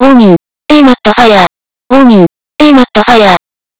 warning_amad_fire.wav